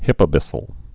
(hĭpə-bĭsəl, hīpə-)